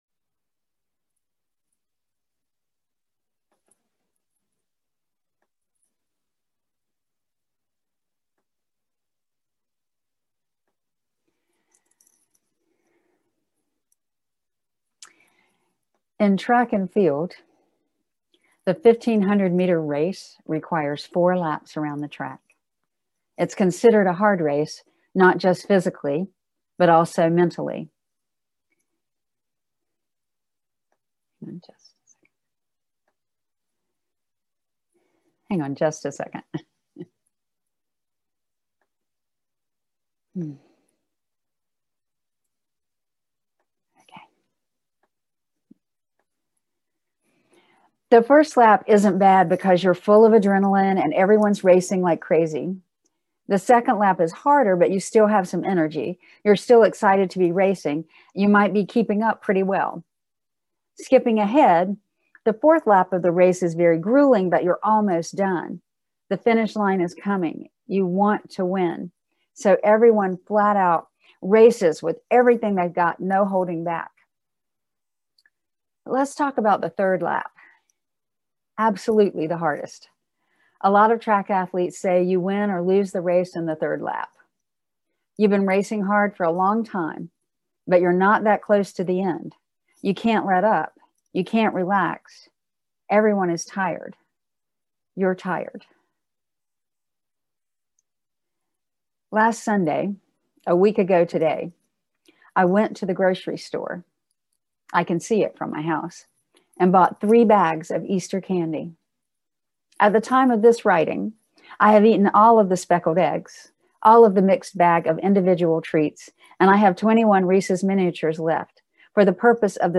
This sermon uses the metaphor of a track race to describe the mental and physical exhaustion felt during the later stages of the pandemic.